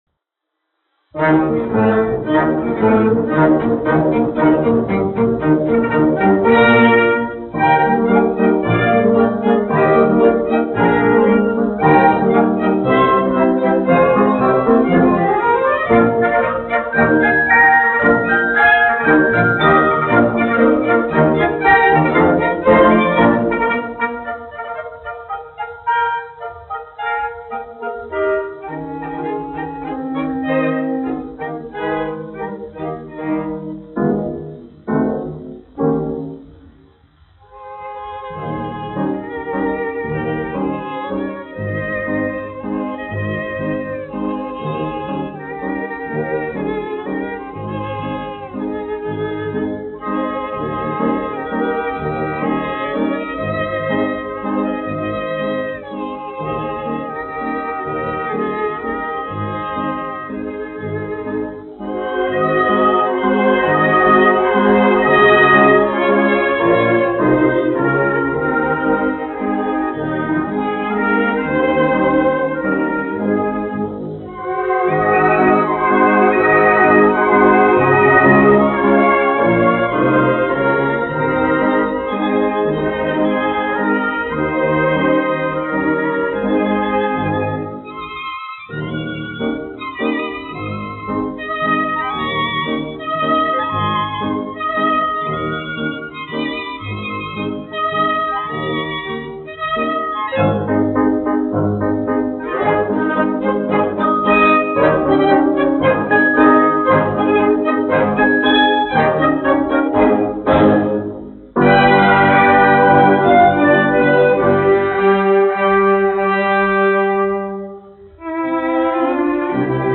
1 skpl. : analogs, 78 apgr/min, mono ; 25 cm
Popuriji
Operetes--Fragmenti